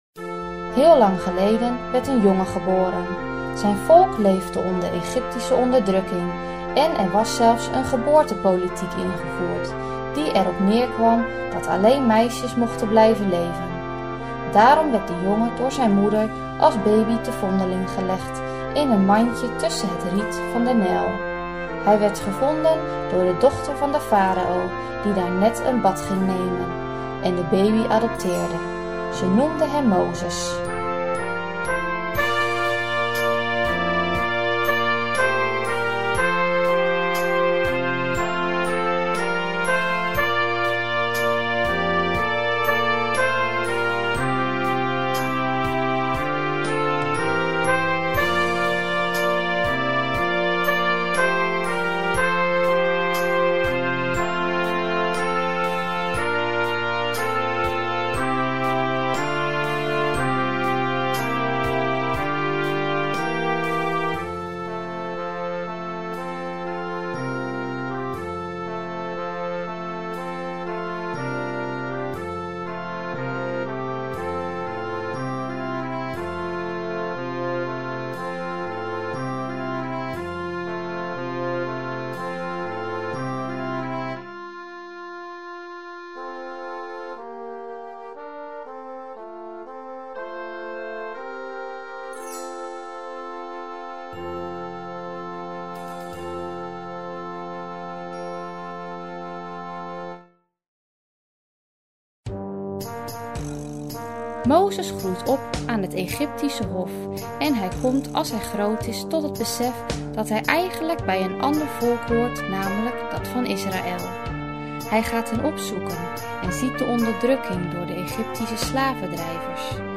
Moses-in-Egypt-narrator.mp3